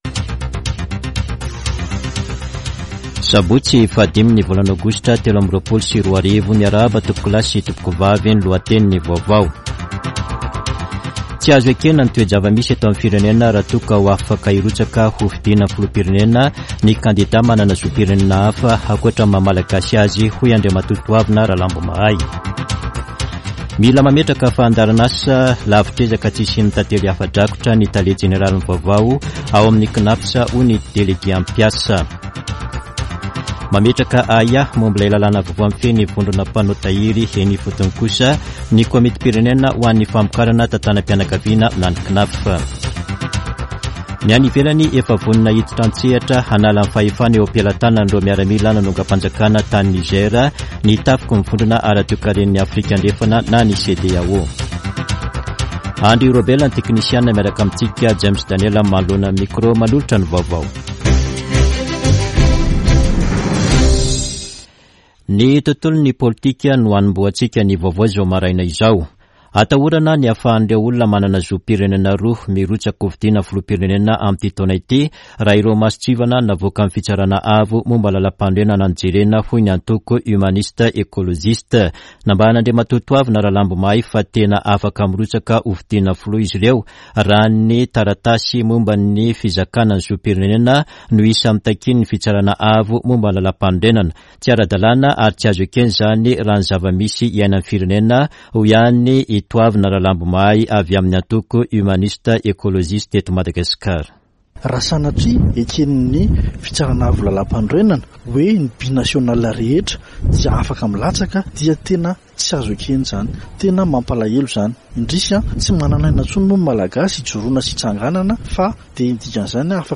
[Vaovao maraina] Sabotsy 5 aogositra 2023